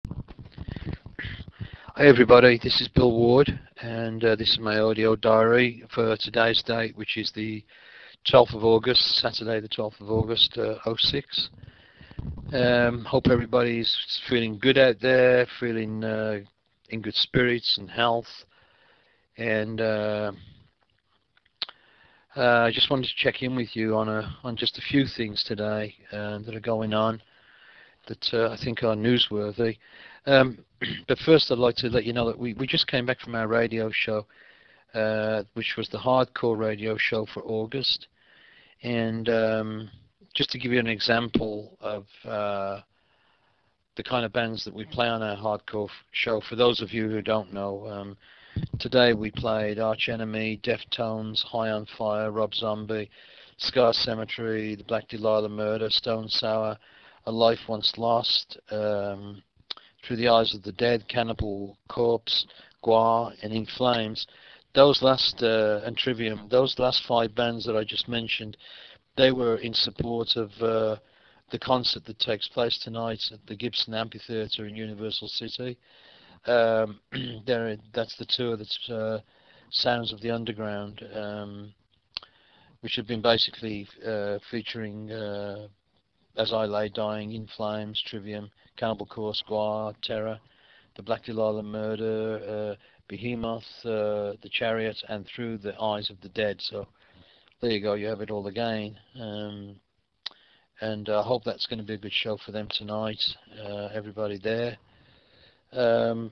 Bill has sent in the the 15th edition of his Audio Diary series.